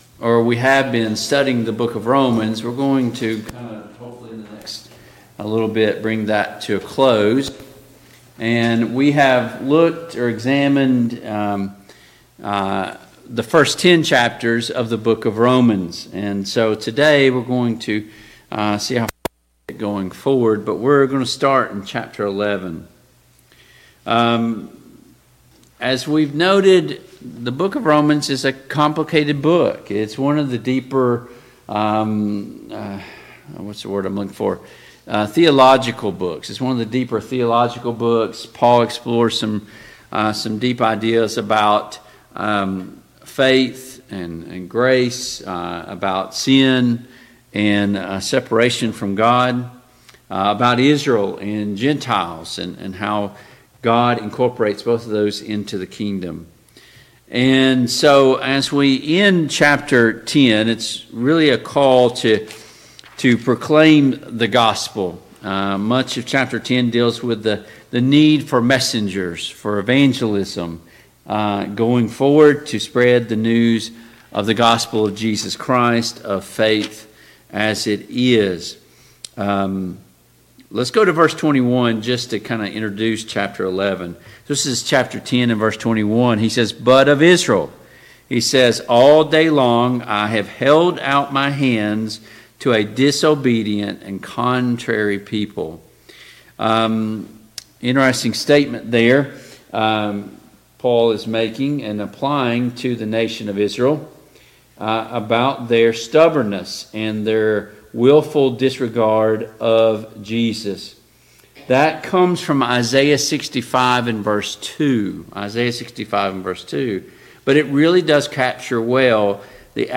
A Study of Romans Passage: Romans 11 Service Type: PM Worship « Who has the greater sin? 5.